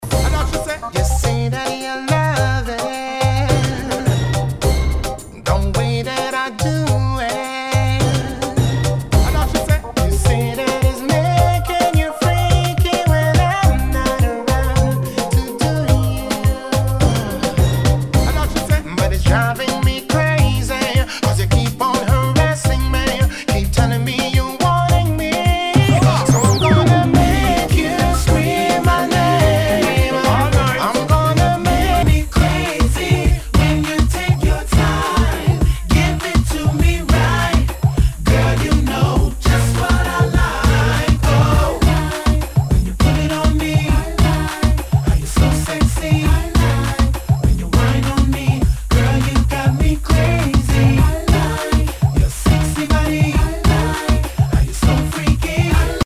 当前位置 > 首页 >音乐 >唱片 >世界音乐 >雷鬼
ナイス！ダンスホール！